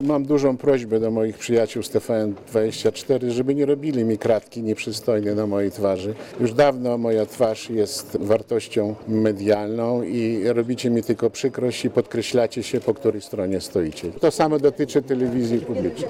Mówi Lew Rywin